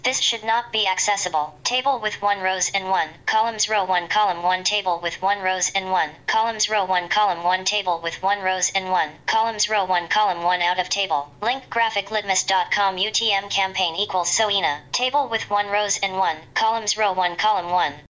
Here’s how a screen reader interprets the code above:
Screen reader: Non-accessible email header